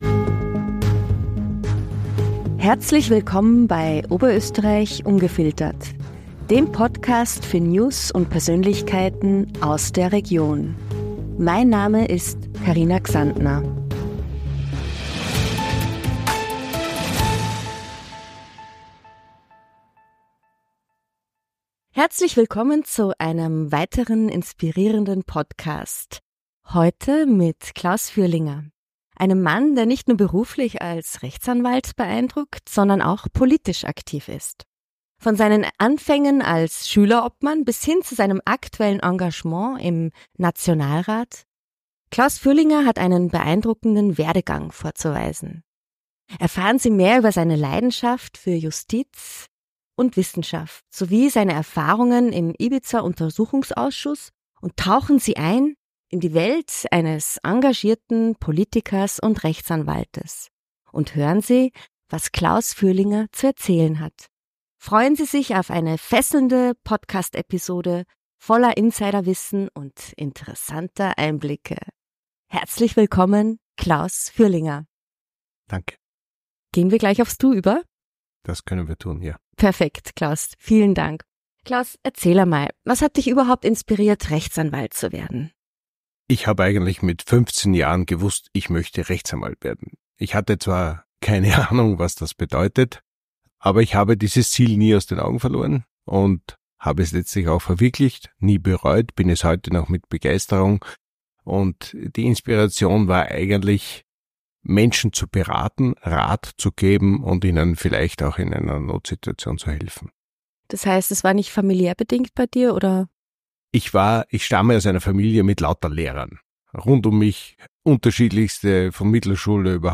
In dieser Folge haben wir Klaus Fürlinger zu Gast, einen Rechtsanwalt und aktiven Politiker, der sich tief in seine berufliche und politische Reise vertieft.